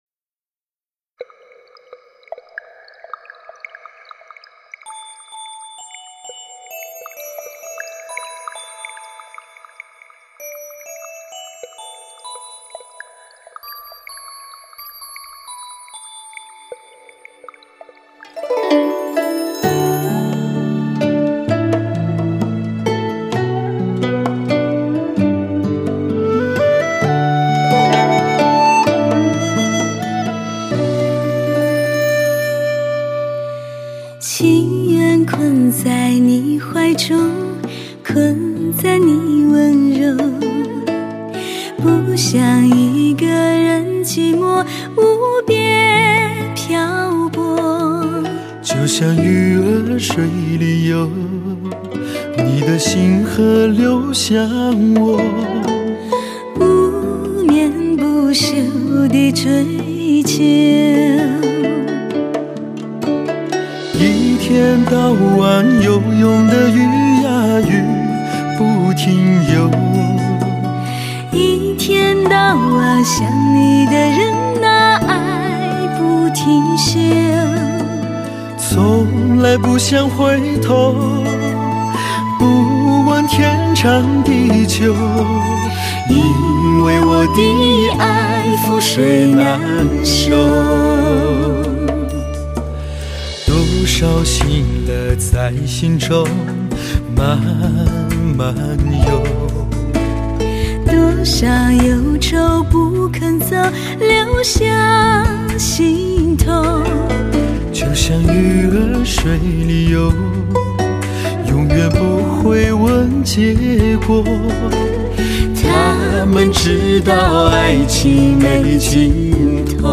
如涓涓溪水，缓缓漫过心田，浸润干涸的心；
又宛若精美的丝绸，随风飘逸，轻舞飞扬。